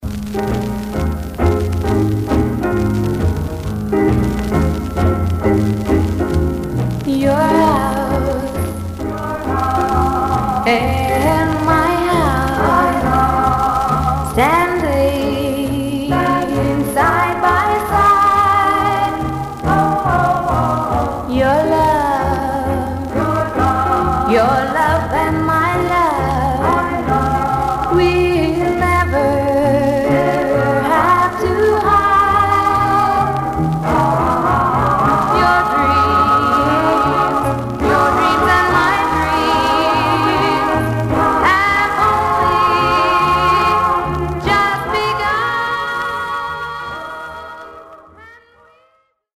Some surface noise/wear Stereo/mono Mono
Rockabilly